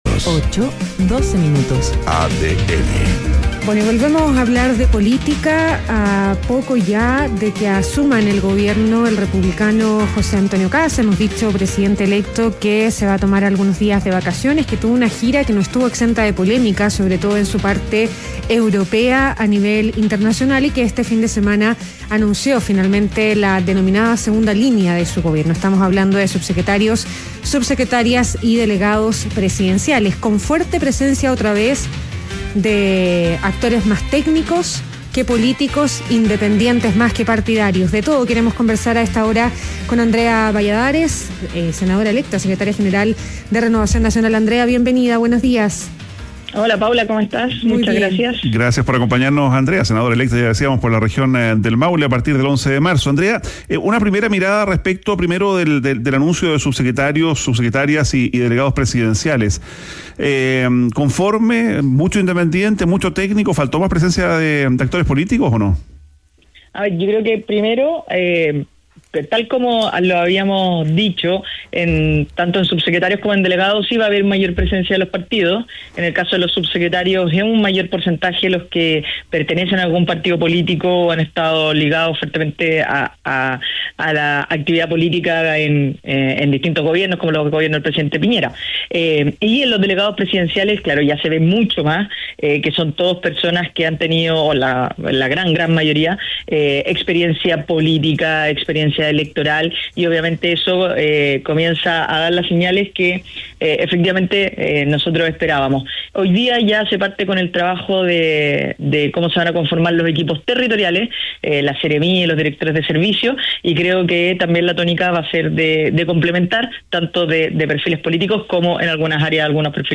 En conversación con ADN Hoy, Andrea Balladares calificó además como un “error” la candidatura de Michelle Bachelet a la Secretaría General de la ONU.